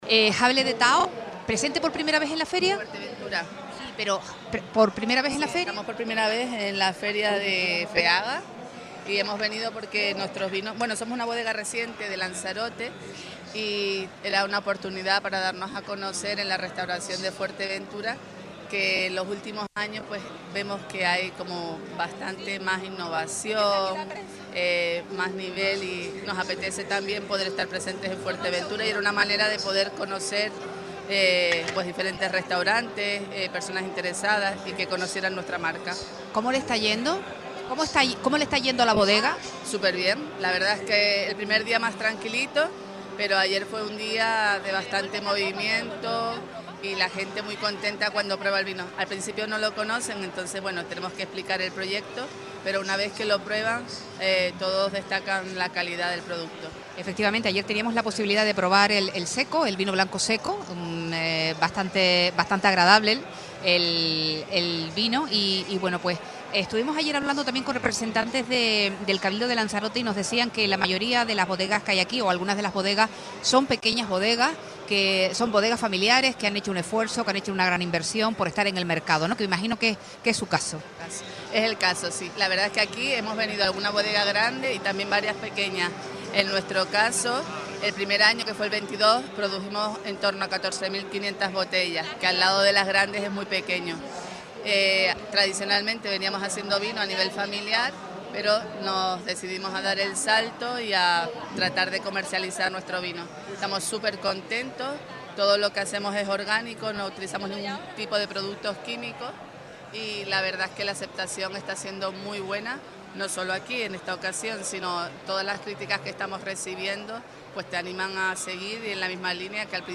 Entrevista Jable de Tao Bodega Lanzarote - Radio Sintonía